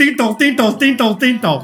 Play, download and share 叮咚叮咚叮咚叮咚 original sound button!!!!